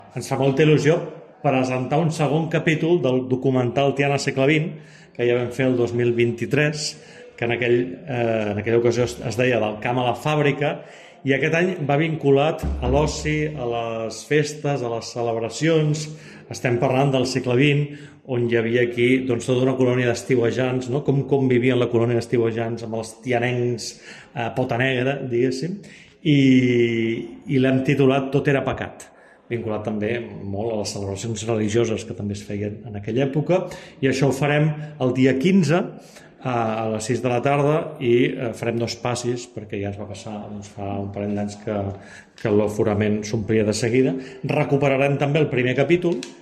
Serà el dilluns 15 de setembre a la Sala Albéniz i, tal com ha explicat l’alcalde, està previst fer dos passis per encabir a tot el públic: